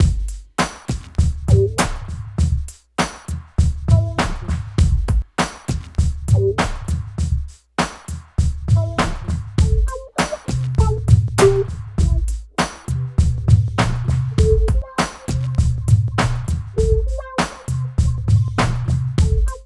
88 LOOP   -L.wav